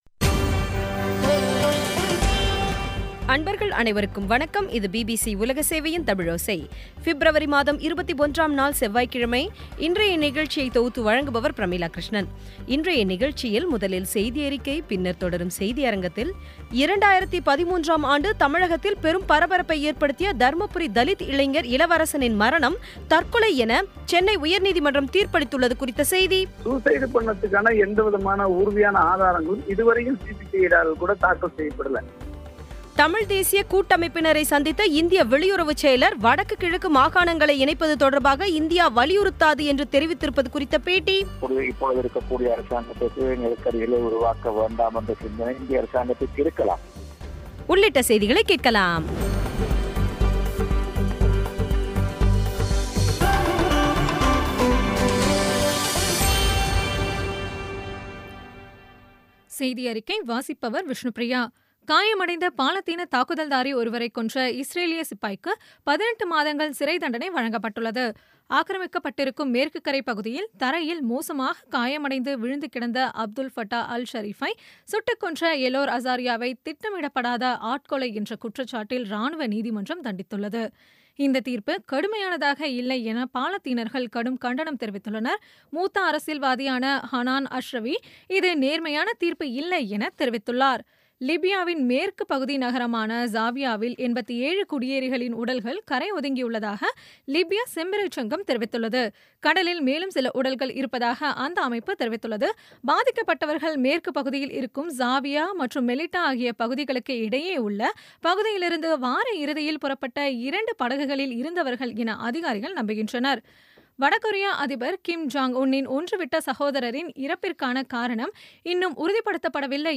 இன்றைய நிகழ்ச்சியில் முதலில் செய்தியறிக்கை